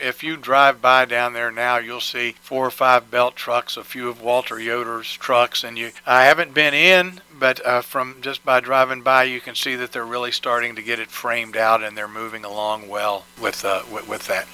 Cumberland Mayor Ray Morriss tells WCBC that passersby can see the changes taking place…